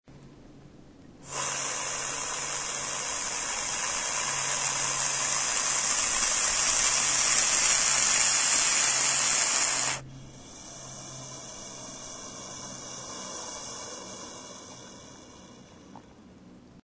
Sonoran Gopher Snake